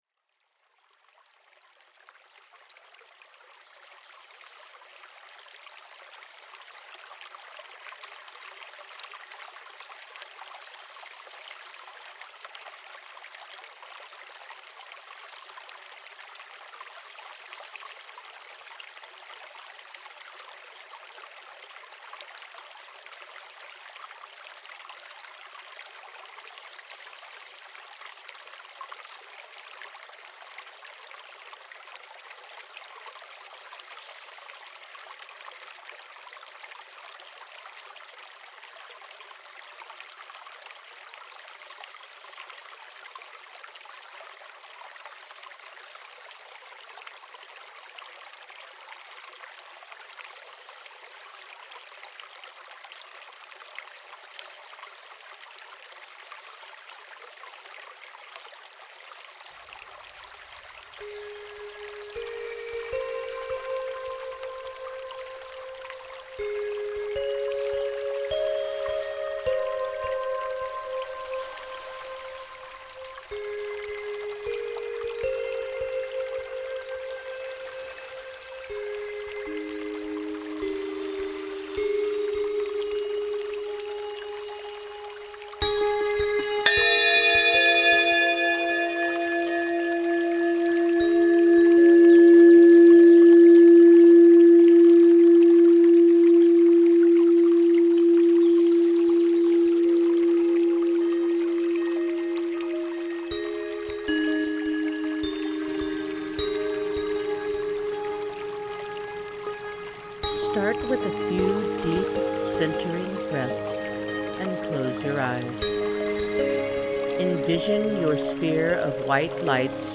• The guided meditation will take you through the rest.
GuidedMeditation-alpha-no-intro.mp3